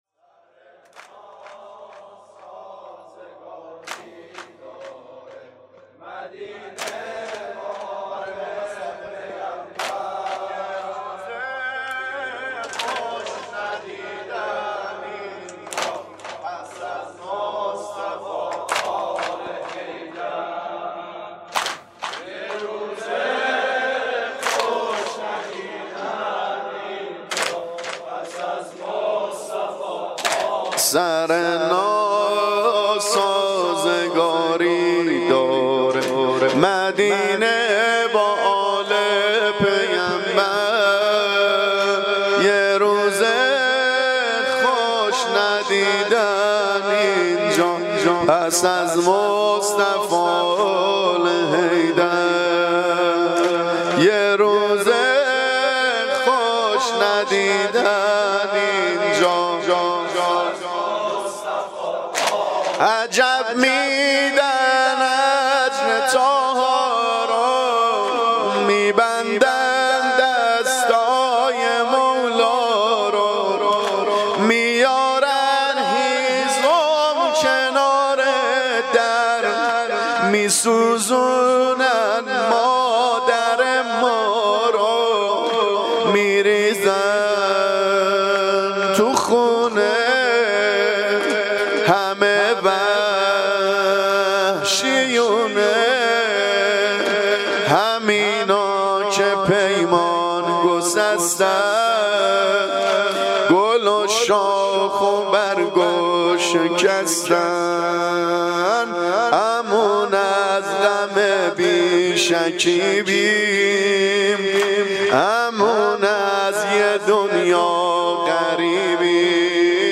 شب اول شهادت امام صادق ع